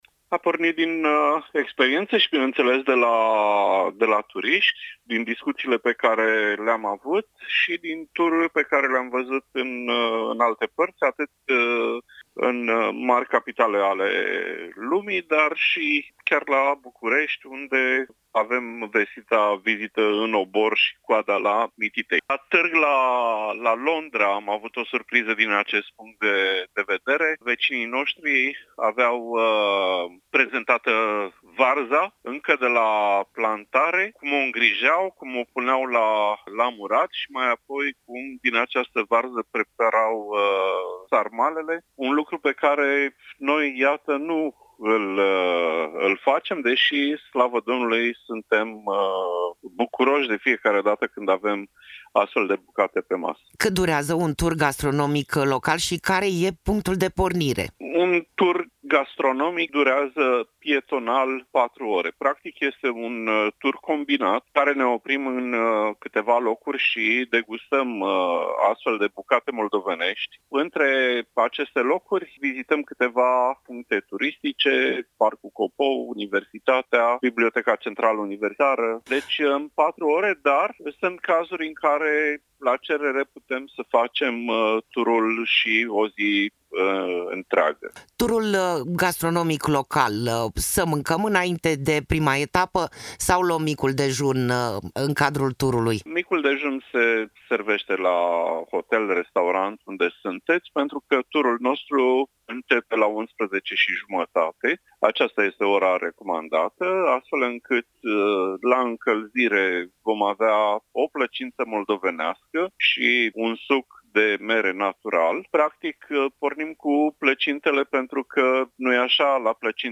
Interviu-tur-gastronomic-local-Iasi.mp3